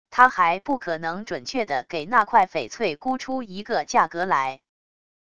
他还不可能准确地给那块翡翠估出一个价格来wav音频生成系统WAV Audio Player